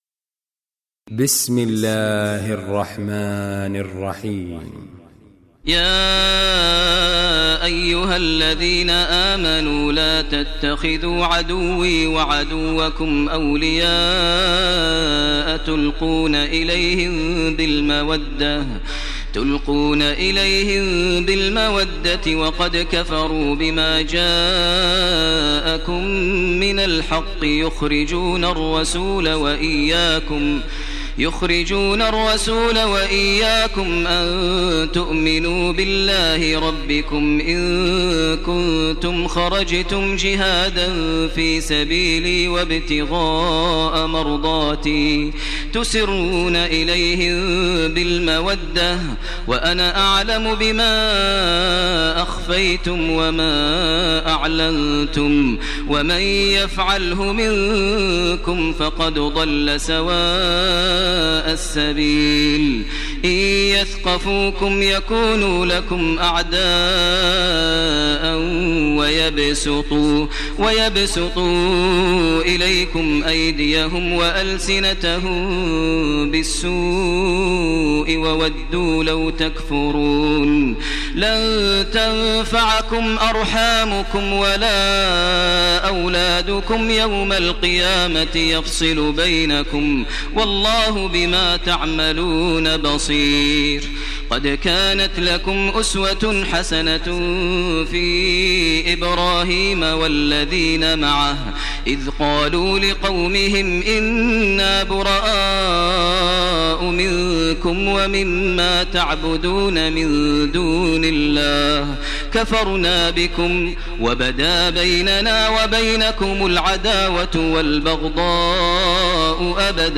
Surah Mumtahina Recitation by Sheikh Maher Mueaqly
Surah Mumtahina, listen online mp3 tilawat / recitation in Arabic in the voice of Imam e Kaaba Sheikh Maher al Mueaqly.